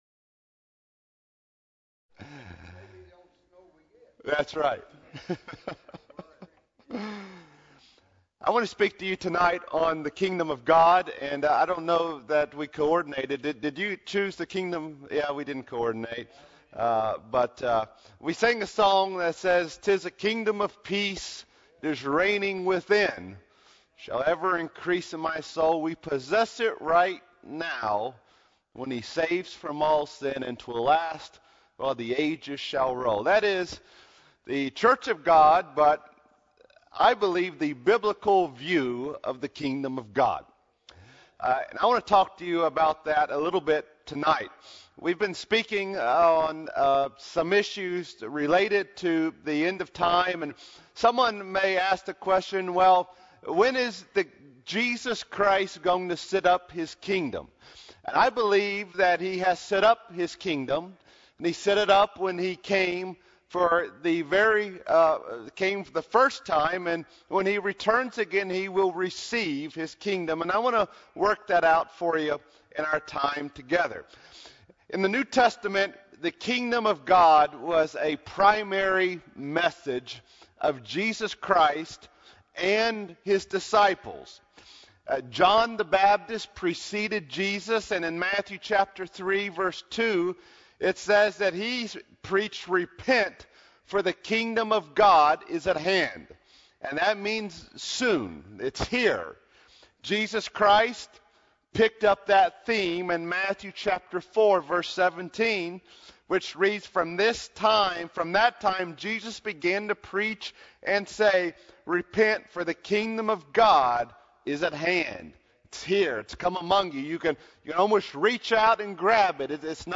Sermon Podcasts / Message Downloads | First Church of God at Greeneville, Tennessee